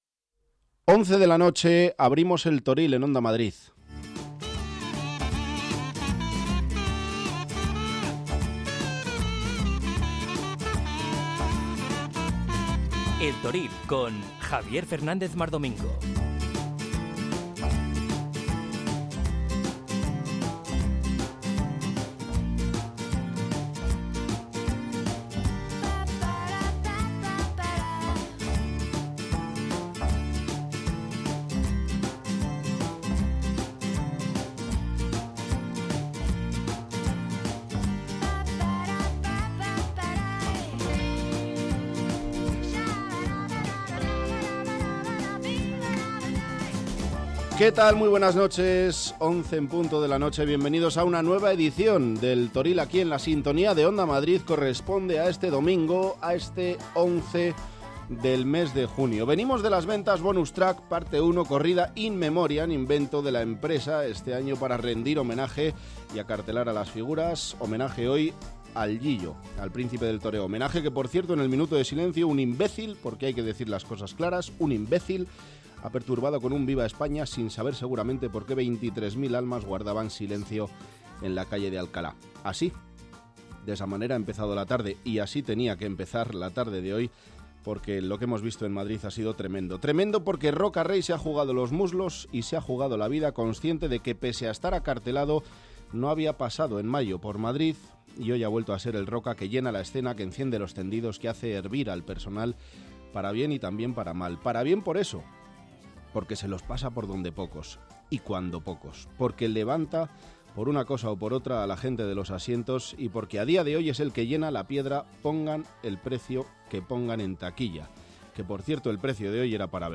Habrá información pura y dura y entrevistas con los principales protagonistas de la semana.